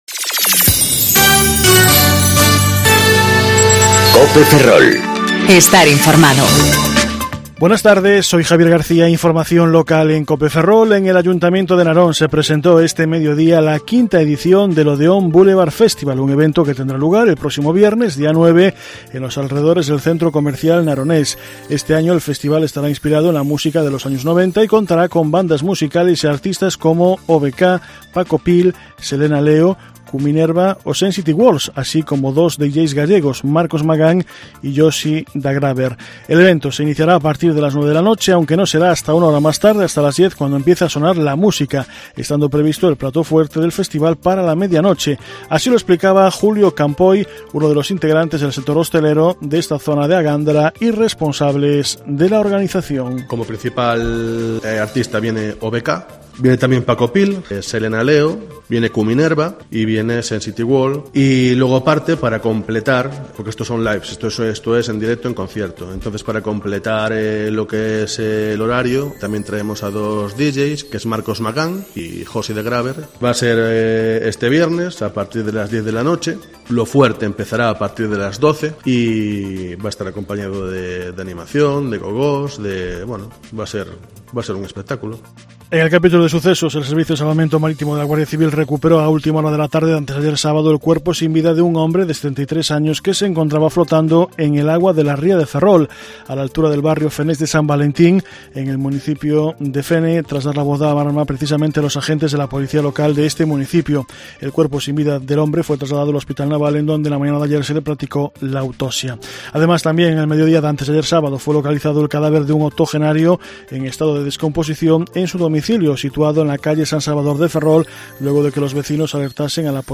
Informativo Mediodía Cope Ferrol 5/8/2019 (De 14.20 a 14.30 horas)